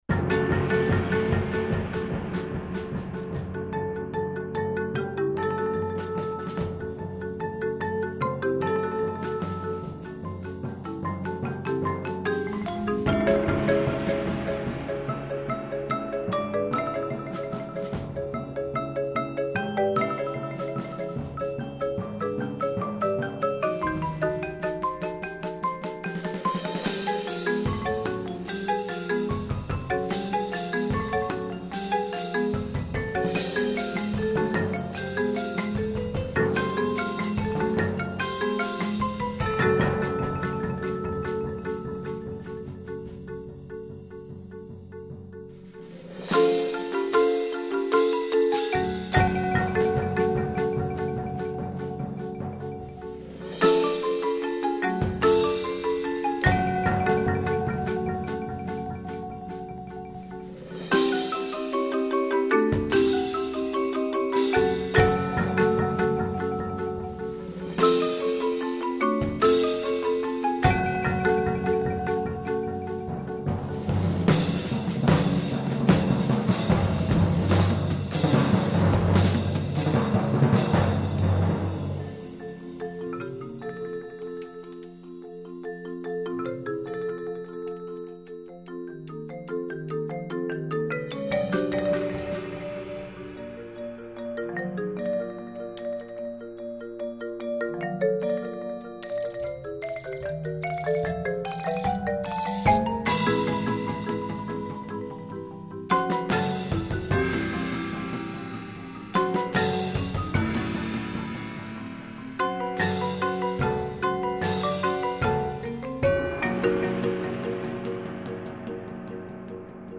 Voicing: Percussion